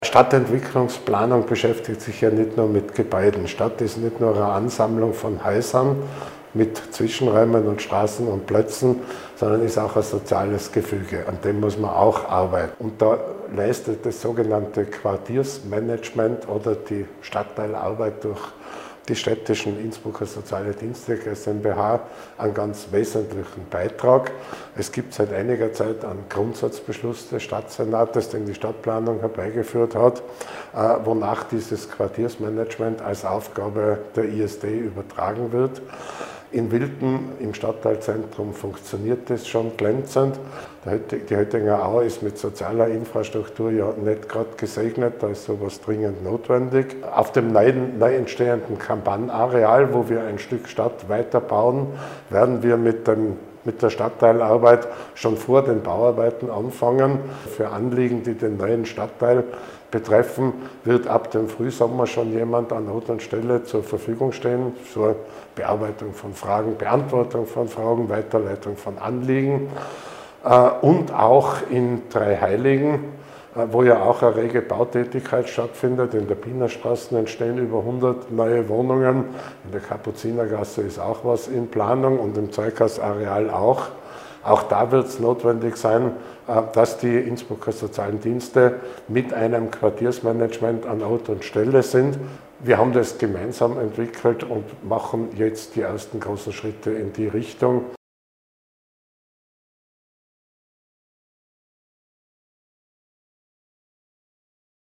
OT von Stadtrat Gerhard Fritz